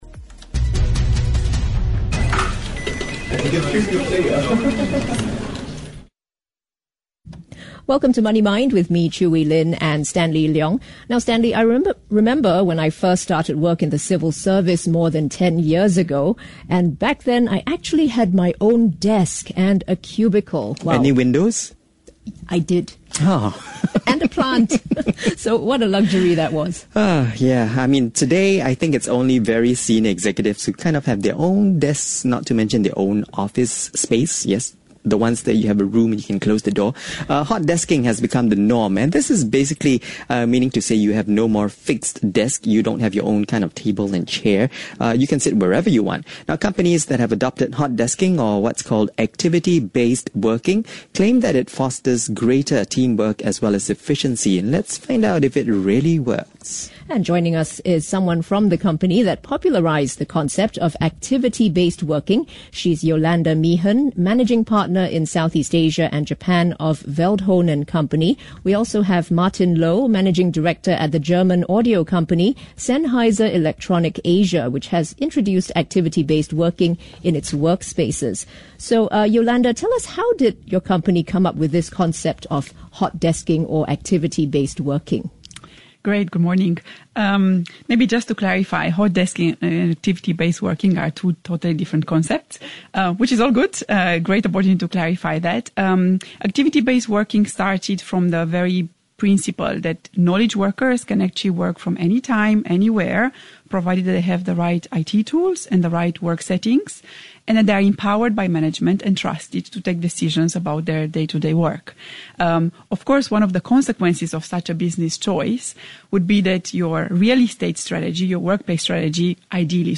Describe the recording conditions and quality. We are on the radio with Money Mind.